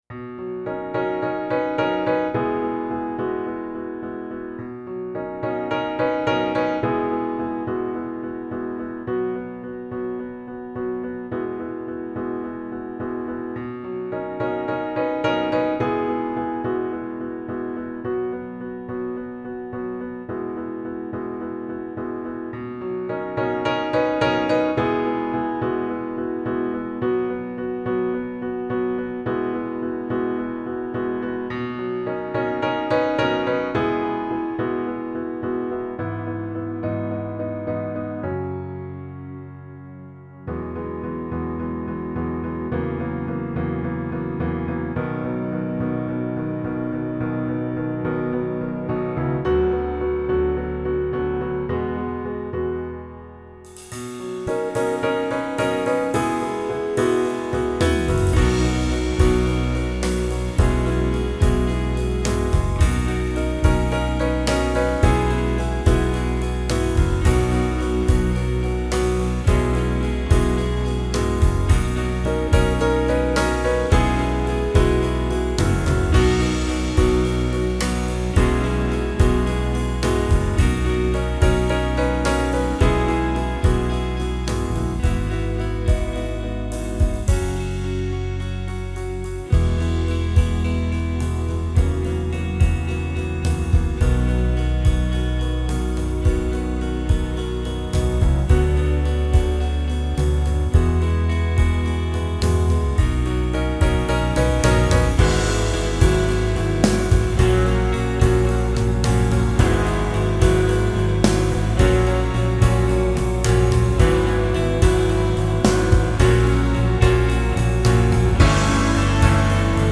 BALLAD 2
Ljudspår utan sång: